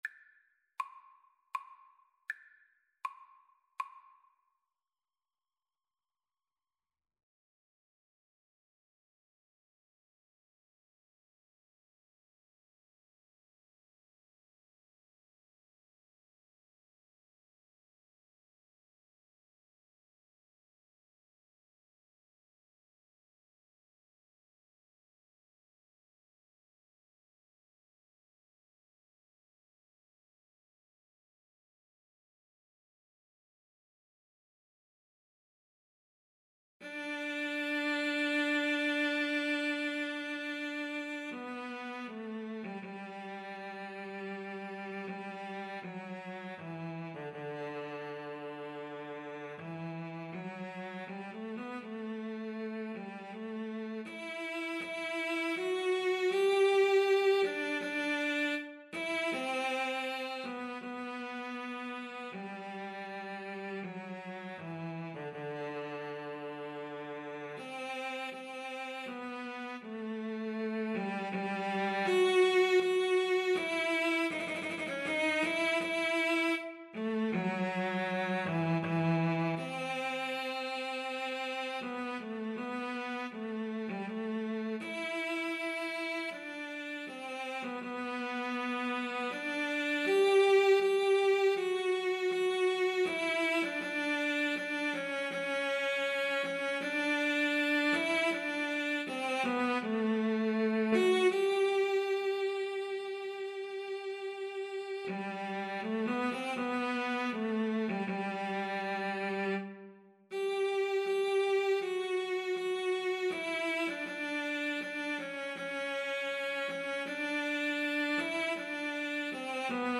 Free Sheet music for Guitar-Cello Duet
CelloGuitar
3/4 (View more 3/4 Music)
Andante grandioso
G major (Sounding Pitch) (View more G major Music for Guitar-Cello Duet )
Classical (View more Classical Guitar-Cello Duet Music)